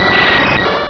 sovereignx/sound/direct_sound_samples/cries/golbat.aif at master
golbat.aif